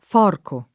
Forco [ f 0 rko ]